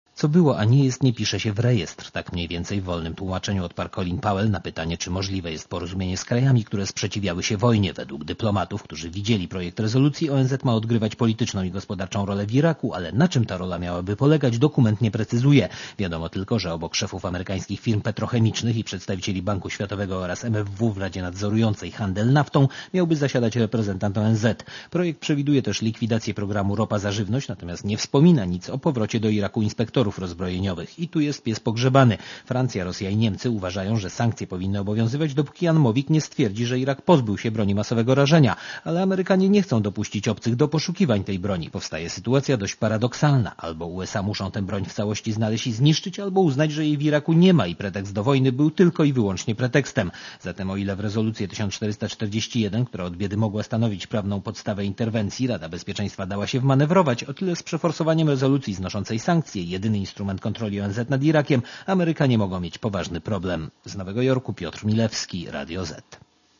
Korespondencja z Nowego Jorku